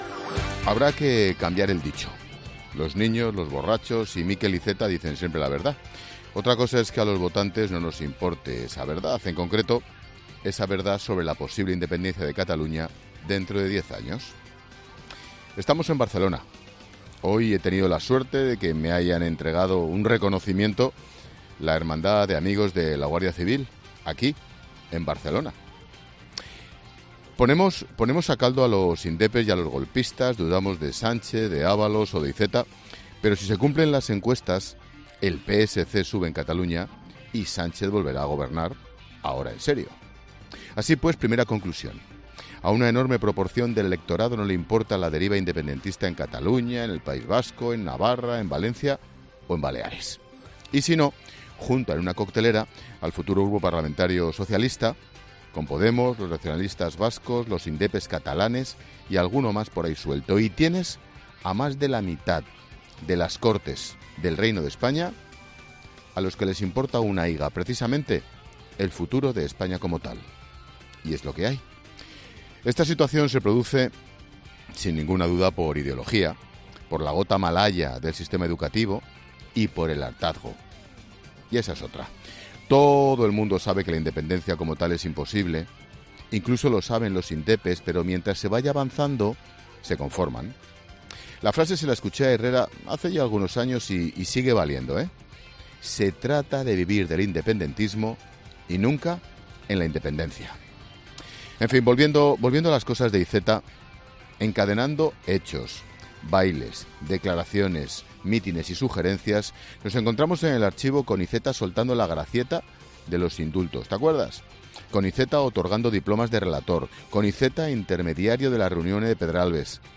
Monólogo de Expósito
Ángel Expósito analiza la actualidad del día en 'La Linterna'